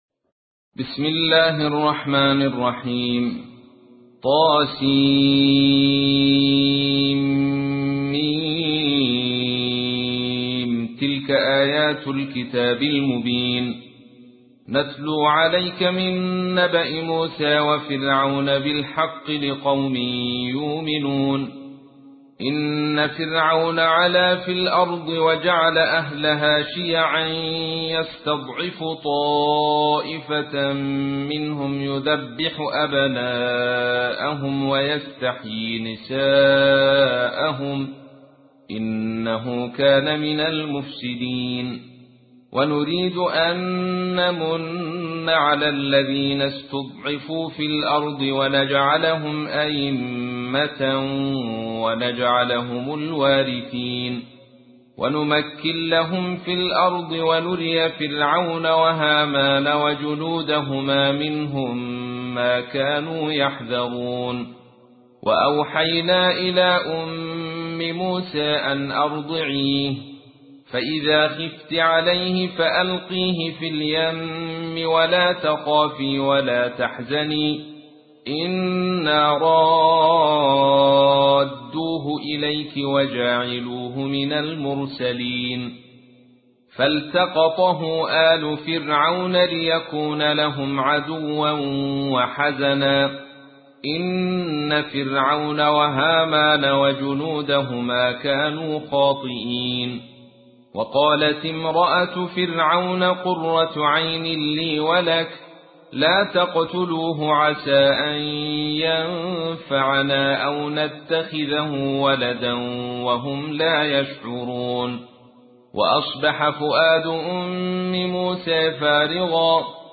تحميل : 28. سورة القصص / القارئ عبد الرشيد صوفي / القرآن الكريم / موقع يا حسين